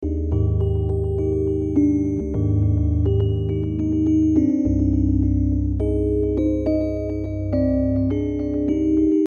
描述：寒冷的Rhodes。
Tag: 104 bpm Chill Out Loops Organ Loops 1.55 MB wav Key : Unknown